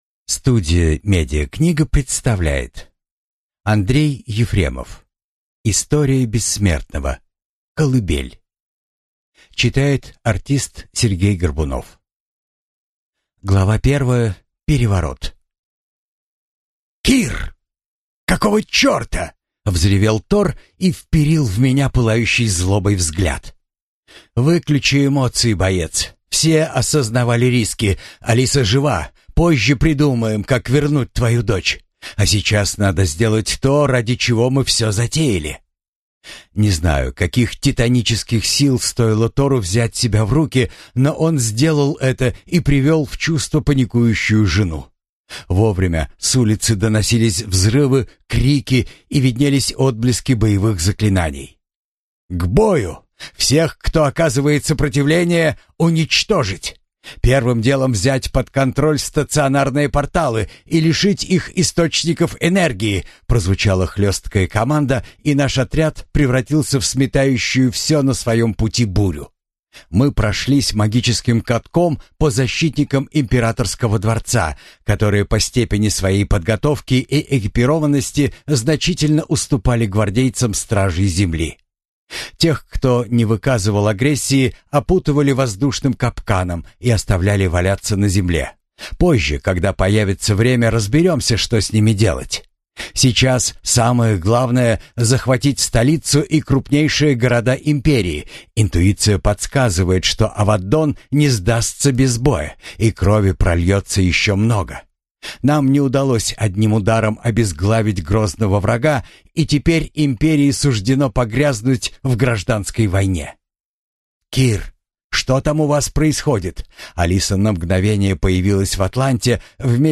Аудиокнига История Бессмертного. Книга 7. Колыбель | Библиотека аудиокниг